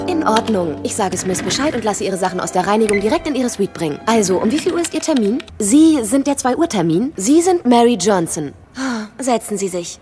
Sekretärin          -